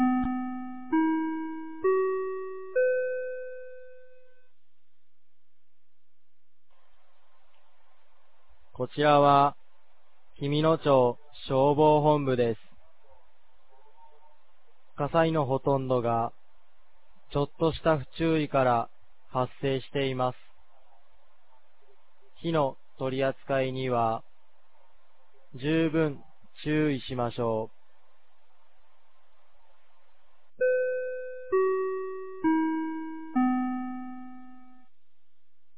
2025年02月01日 16時00分に、紀美野町より全地区へ放送がありました。